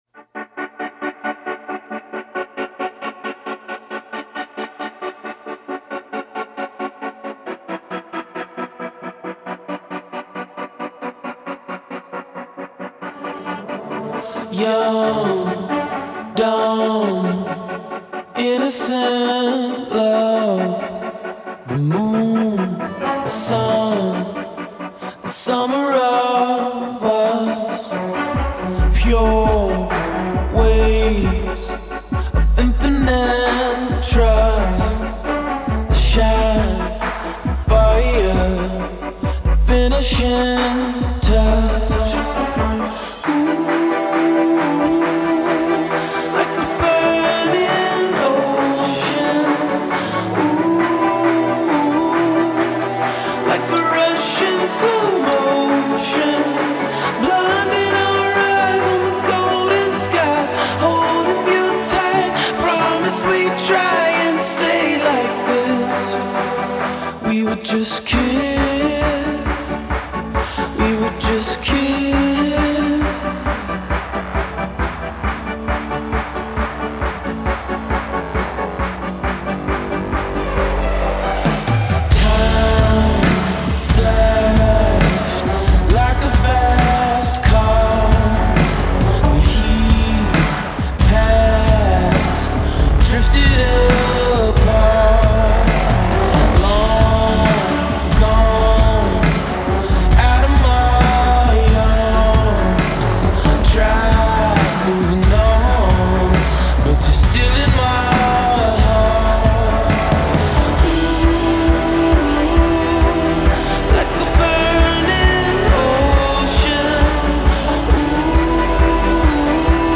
Recorded in Nashville, TN and Los Angeles, CA